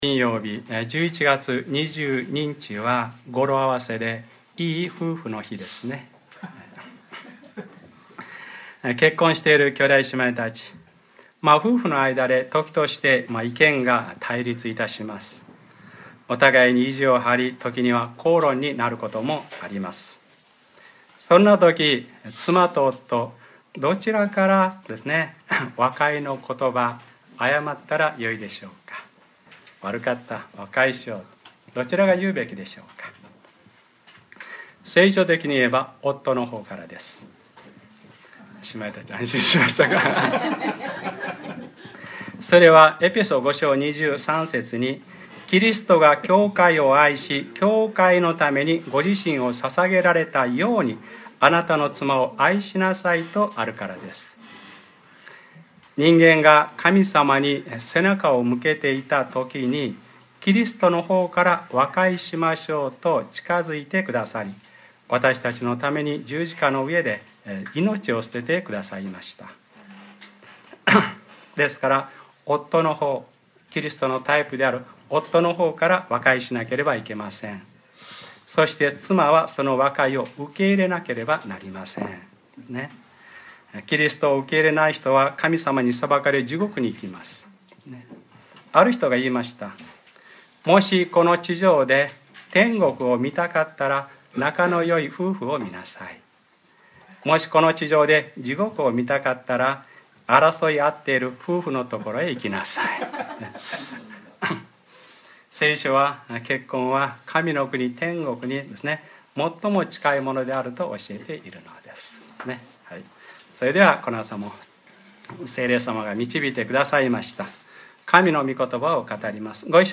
2013年11月17日（日）礼拝説教 『ルカｰ２９：あなたの信仰があなたを直したのです』 | クライストチャーチ久留米教会